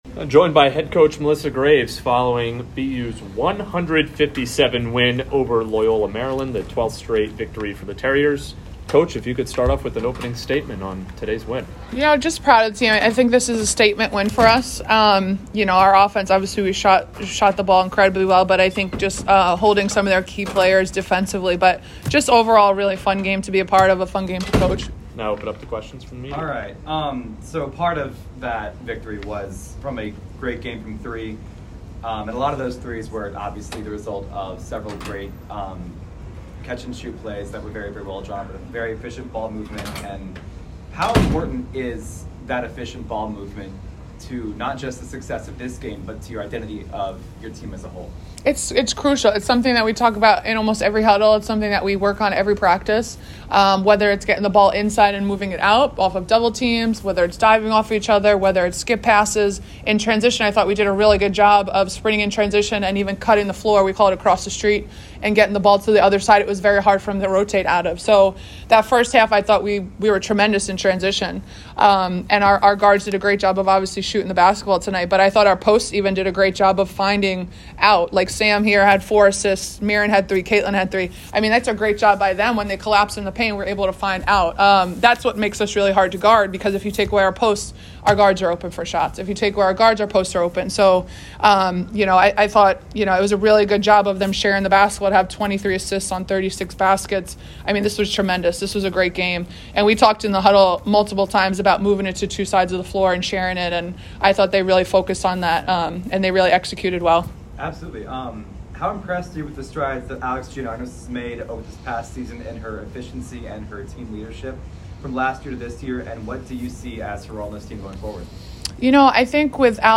WBB_Loyola_2_Postgame.mp3